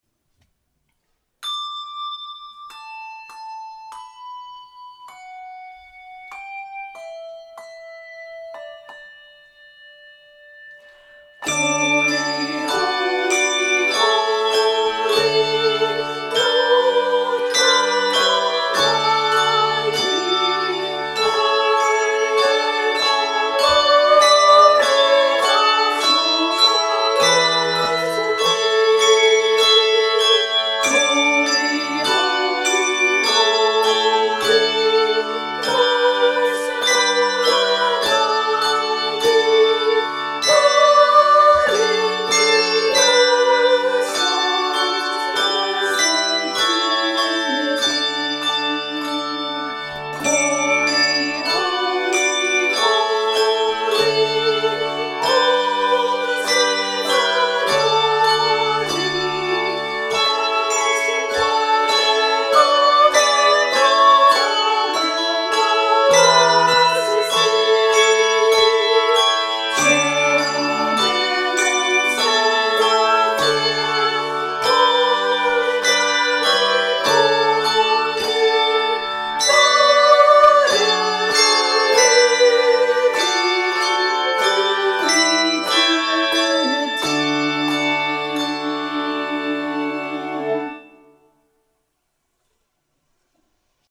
Voicing: Handbells 2-5 Octave